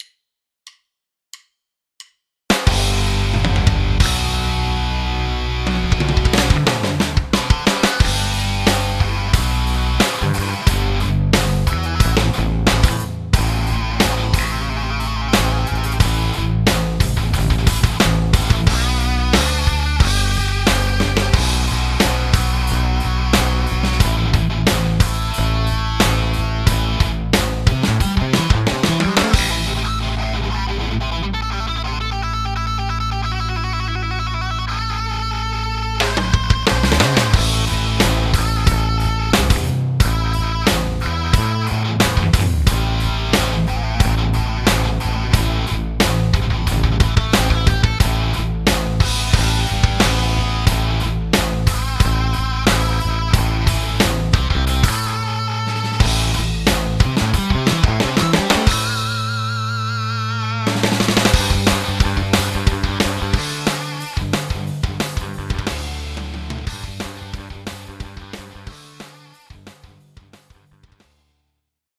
Prominy lance une banque de samples dédiée à la Les Paul Custom au format GigaStudio (2 et 3), la LPC Electric Distortion and Clean Guitar.
merci pour les conseils et j'ai essayé en doublant les pistes guitares :